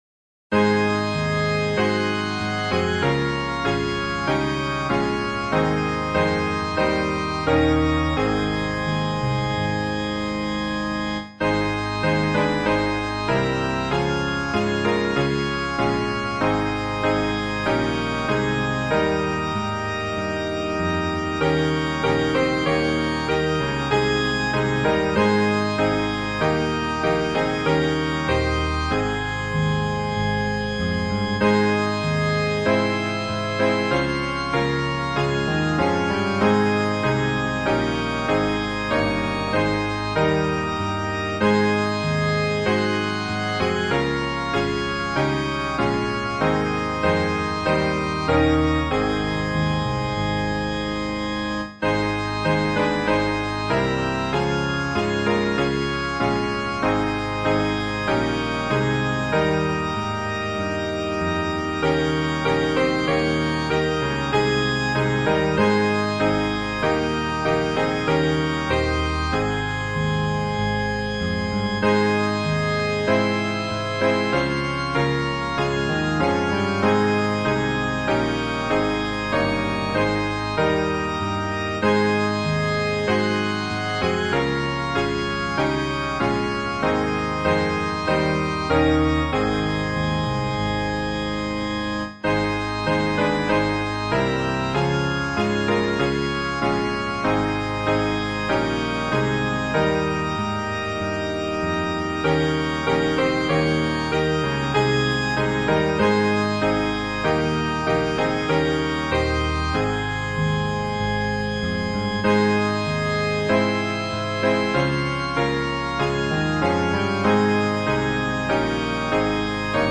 伴奏
这是一首福音的进行曲。